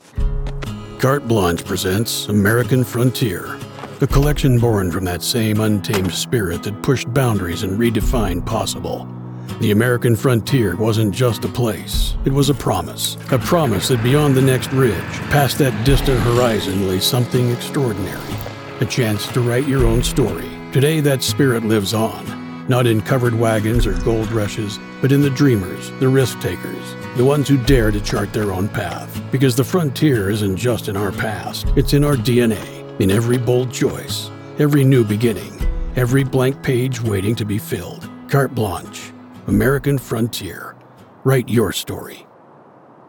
Pacific Northwest Voice Actor
My delivery is grounded, cinematic, and built for brands that value authenticity over flash.
Commercial Demo